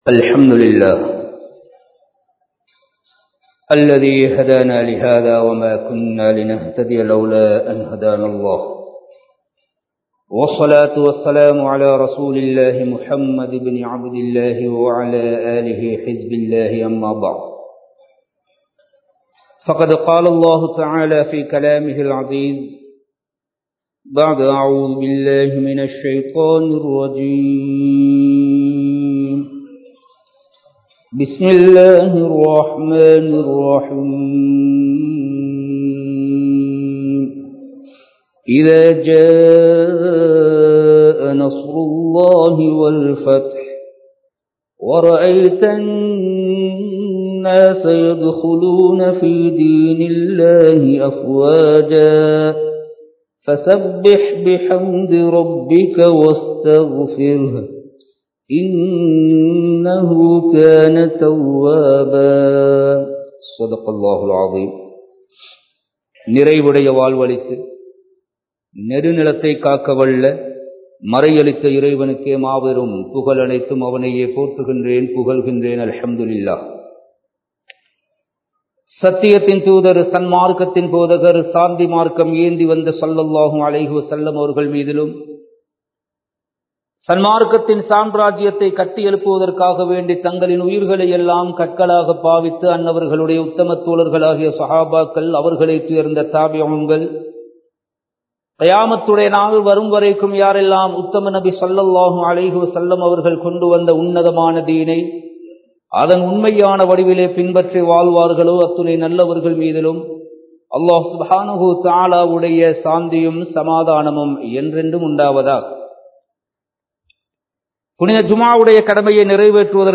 பாவங்களை விட்டு விடுங்கள் | Audio Bayans | All Ceylon Muslim Youth Community | Addalaichenai
Kandy, Kattukela Jumua Masjith